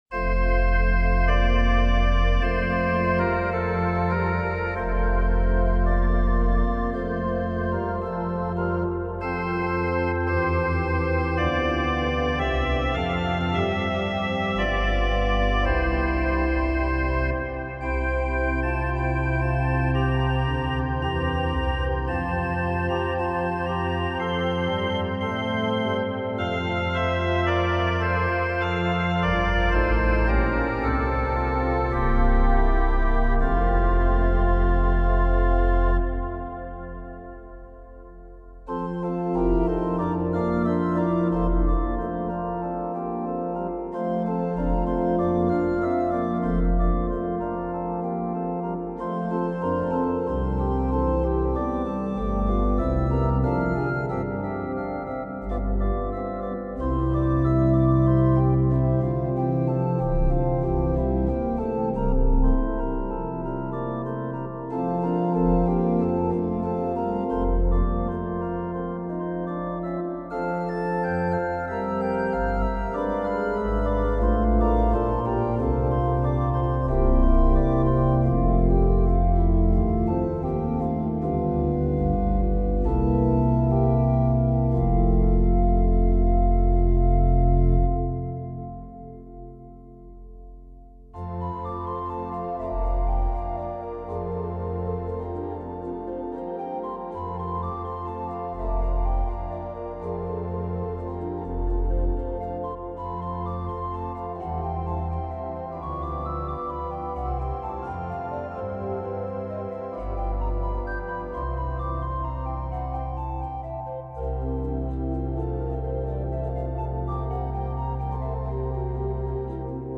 Das zweite Manual etwas softer als das erste.